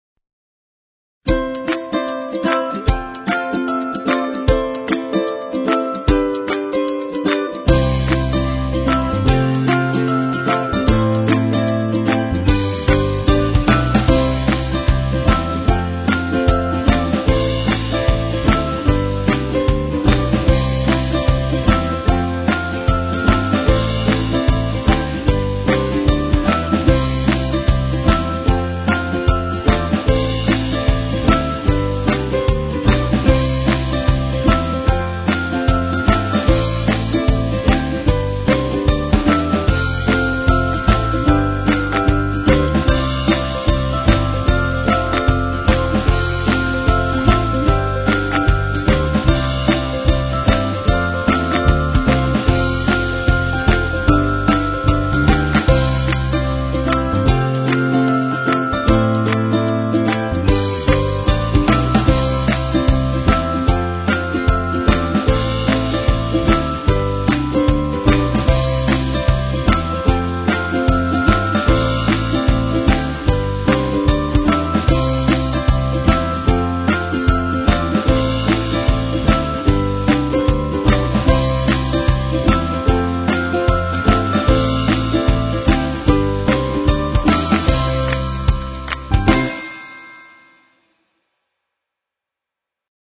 描述：这是一首用尤克里里琴演奏的非常快乐和欢快的曲子。 还具有拍手，铃声，和原声吉他，给一个愉快和积极的氛围。
Sample Rate 抽样率16-Bit Stereo 16位立体声, 44.1 kHz
Tempo (BPM) 节奏(BPM)150